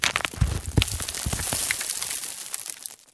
dirt.wav